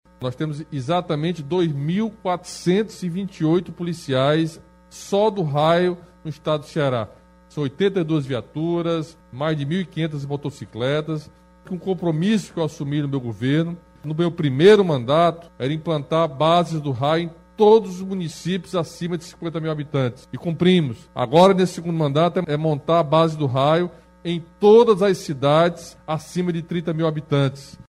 O governador Camilo Santana destacou o contingente da corporação e falou sobre a estrutura de ampliação.
11.11-CAMILO-1-ESTRUTURA.mp3